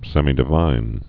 (sĕmē-dĭ-vīn, sĕmī-)